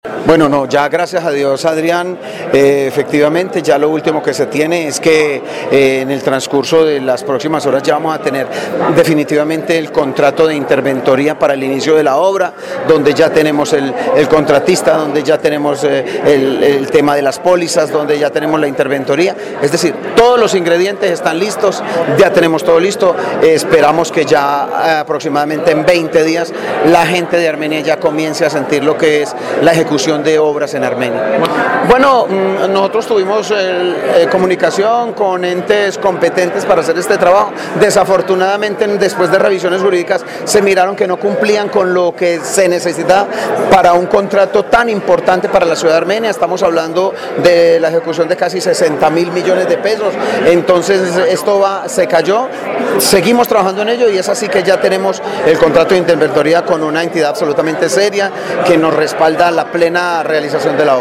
En el noticiero del mediodía de Caracol Radio Armenia el alcalde de la capital del Quindío, James Padilla García hablo de las obras viales en la ciudad y las dificultades que han tenido con la adjudicación del contrato de interventoría de este proyecto de 60.000 millones de pesos y que es fundamental para poder dar inició a los trabajos.